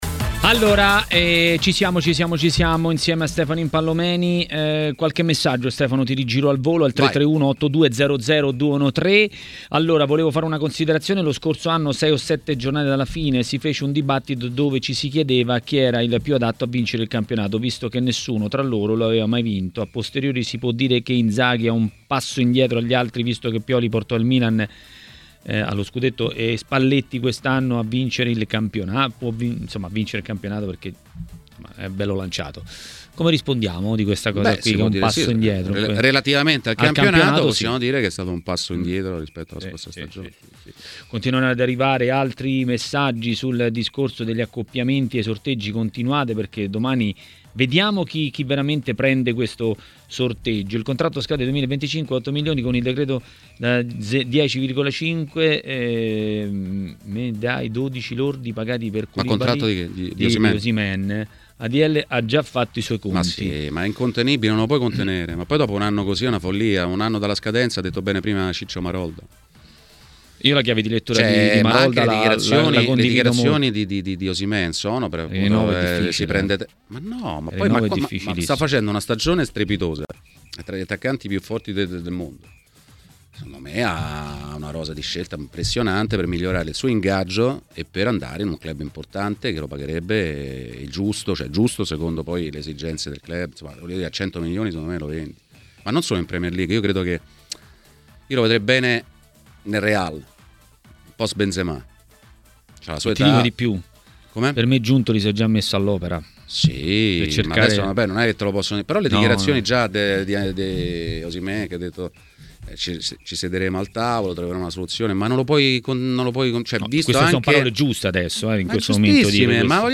Nel corso di Maracanà su TMW Radio, l'ex calciatore Stefano Impallomeni ha parlato della gara di questa sera della Roma sul campo della Real Sociedad.